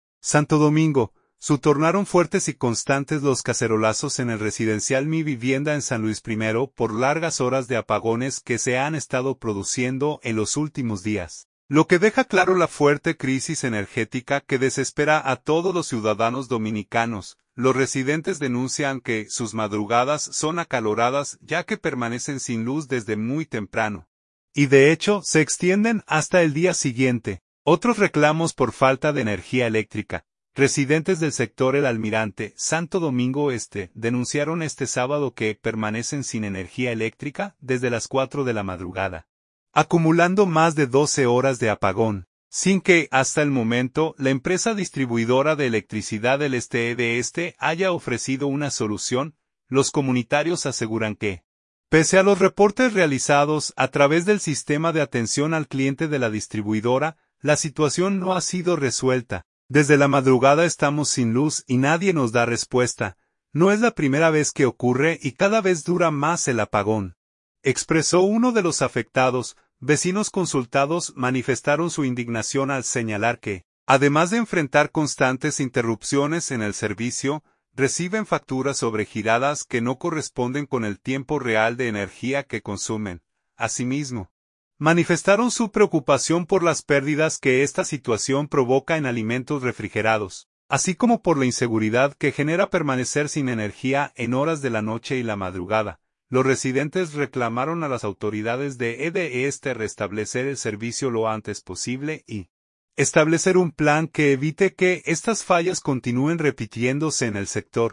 Vídeo | Arman cacerolazos en "Mi Vivienda" San Luis por largos apagones
Santo Domingo. – Se tornaron fuertes y constantes los cacerolazos en el residencial "Mi Vivienda" en San Luis I, por largas horas de apagones que se han estado produciendo en los últimos días, lo que deja claro la fuerte crisis energética que desespera a todos los ciudadanos dominicanos.